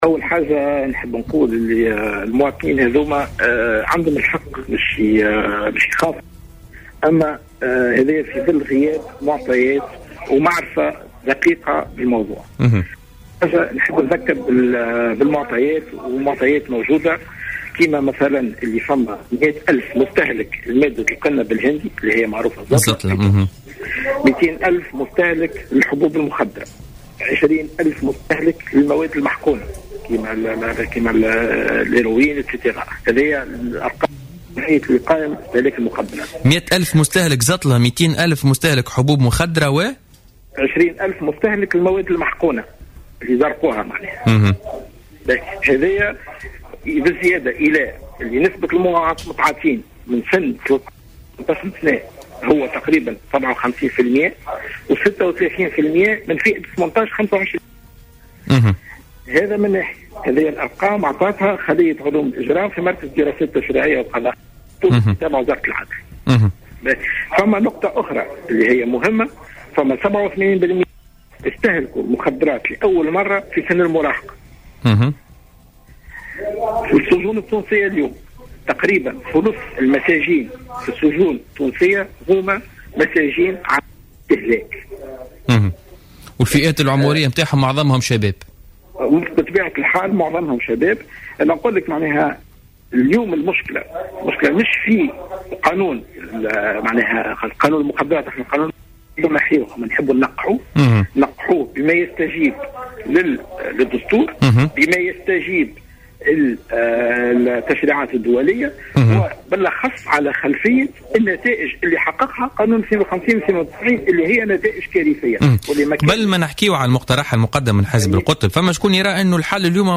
ضيف برنامج "بوليتيكا"